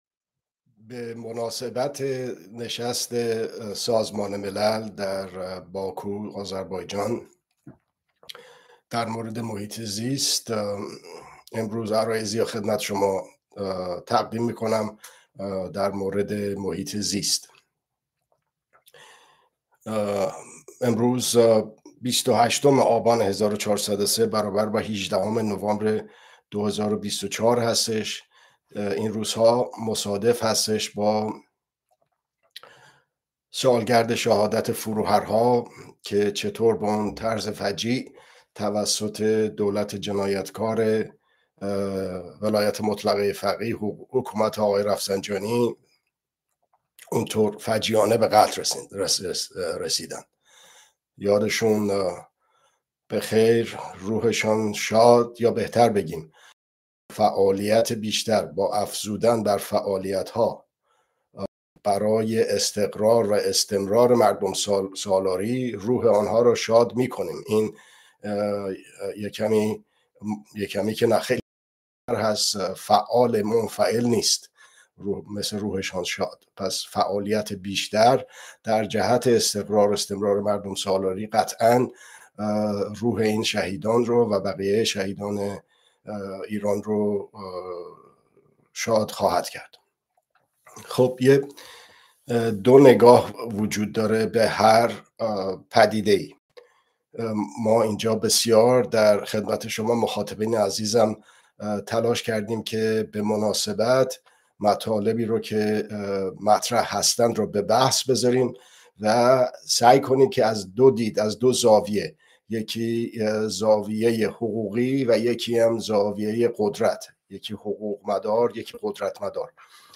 گزارش، بررسی، و ارزیابی رویدادها ❊ (دوشنبه‌ها ساعت۲۱ به‌وقت ایران به‌صورت زنده)